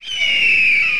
fly.wav